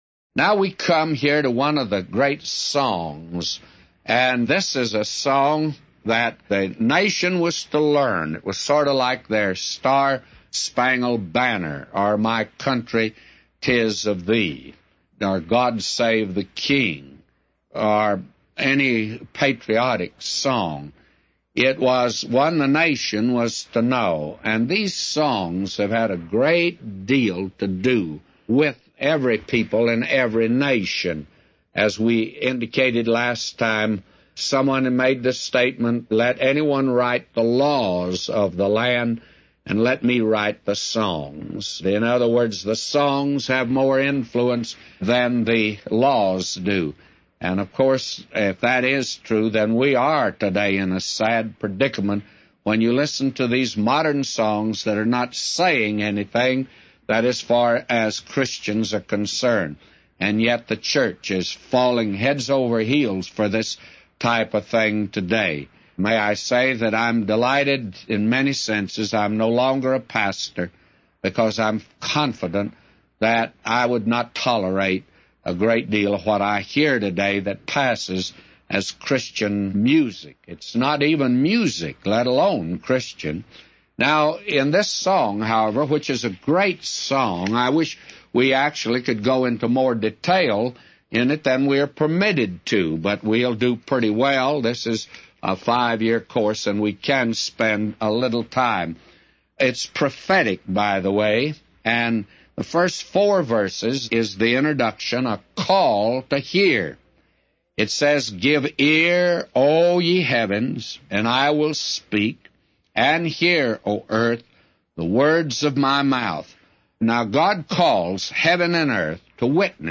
A Commentary By J Vernon MCgee For Deuteronomy 31:24-999